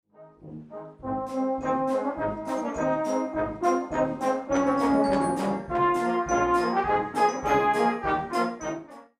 light German-style march